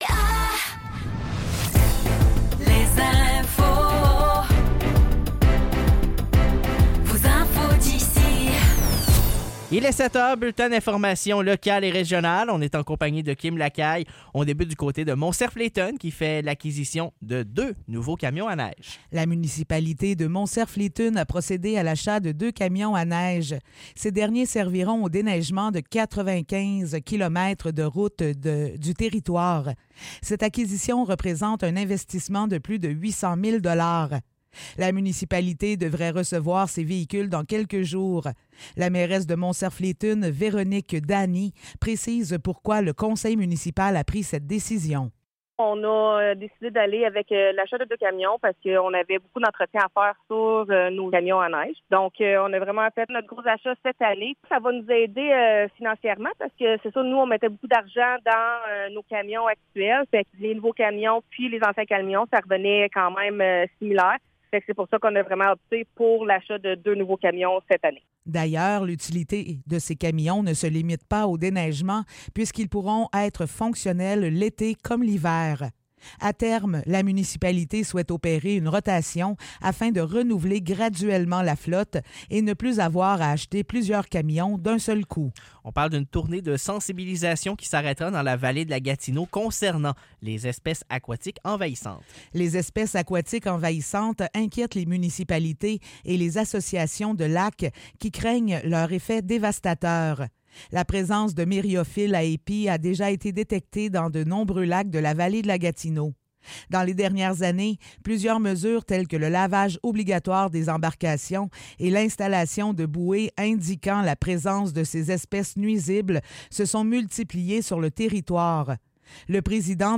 Nouvelles locales - 20 juin 2024 - 7 h